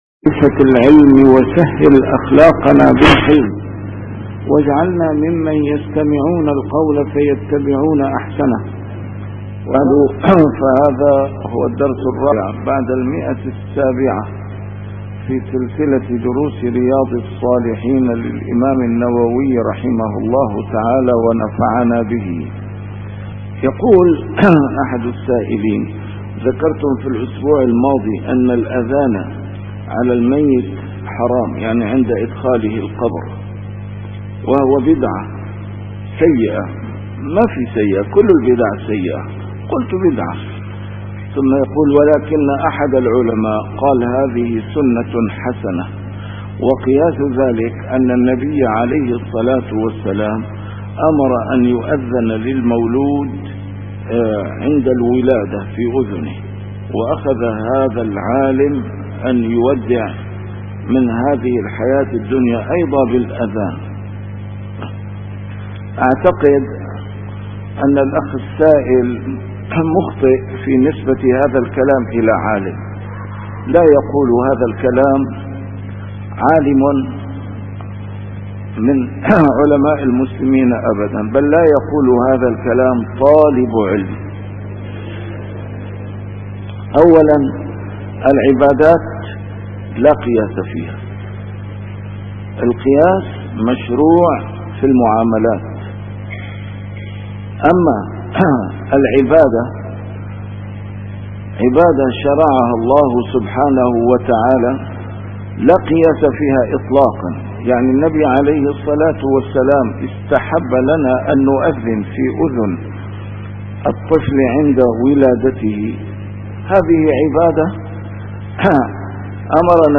A MARTYR SCHOLAR: IMAM MUHAMMAD SAEED RAMADAN AL-BOUTI - الدروس العلمية - شرح كتاب رياض الصالحين - 704- شرح رياض الصالحين: فيما يدعى به للمريض